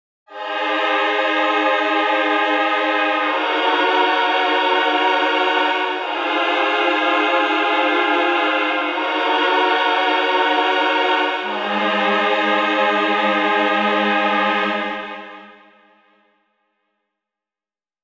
FLUTES & ORCHESTRA SEQUENCE